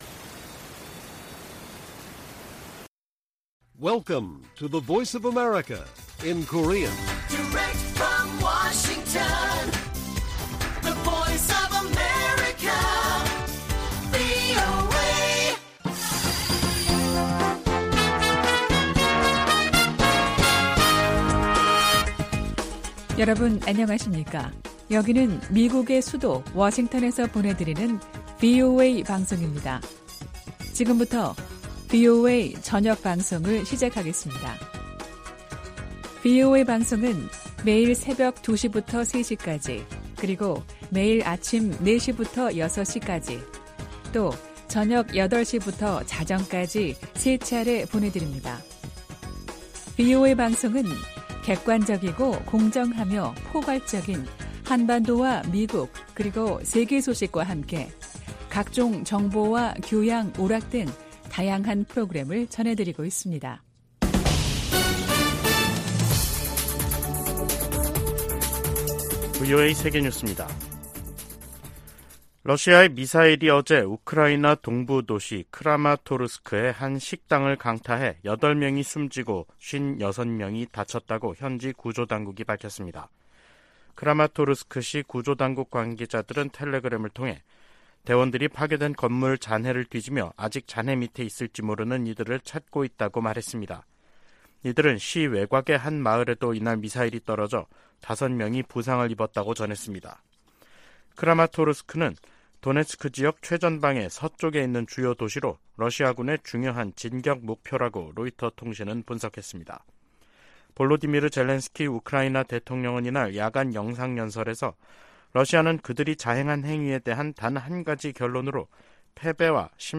VOA 한국어 간판 뉴스 프로그램 '뉴스 투데이', 2023년 6월 28일 1부 방송입니다. 미 국무부는 미국과 한국의 군사활동 증가와 공동 핵계획 탓에 한반도 긴장이 고조되고 있다는 중국과 러시아의 주장을 일축했습니다. 미 국방부는 북한의 핵무력 강화 정책 주장과 관련해 동맹국과 역내 파트너들과의 협력을 강조했습니다. 미 하원 세출위원회가 공개한 2024회계연도 정부 예산안은 북한과 관련해 대북 방송과 인권 증진 활동에만 예산을 배정하고 있습니다.